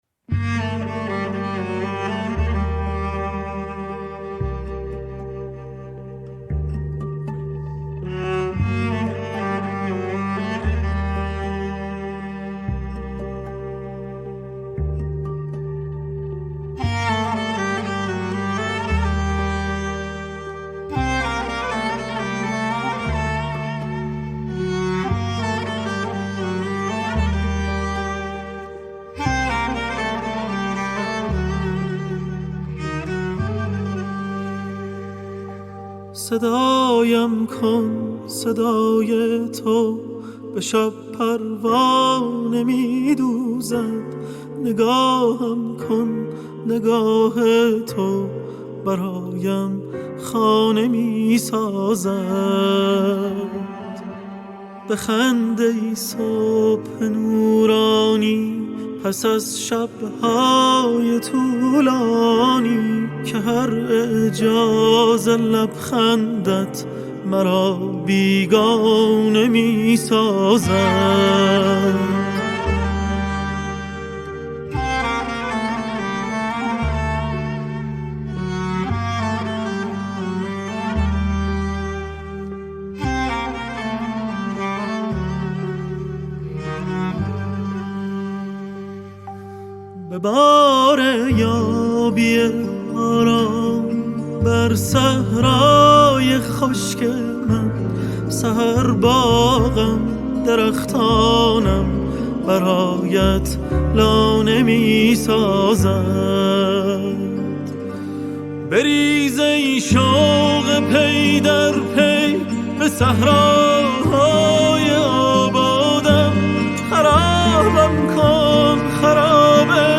jazz
World_Music